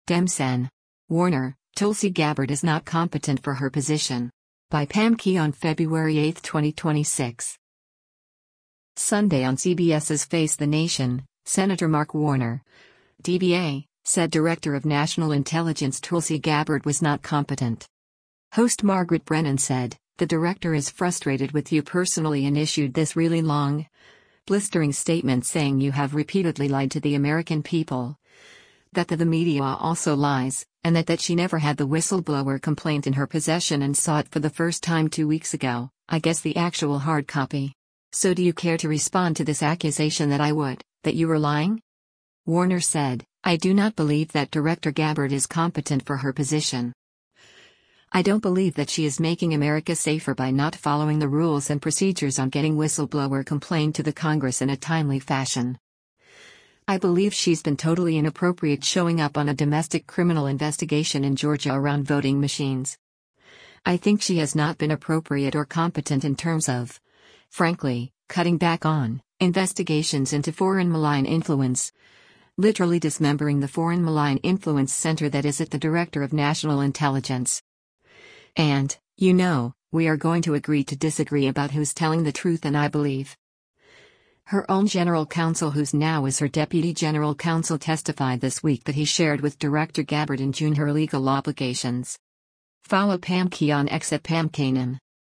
Sunday on CBS’s “Face The Nation,” Sen. Mark Warner (D-VA) said Director of National Intelligence Tulsi Gabbard was not competent.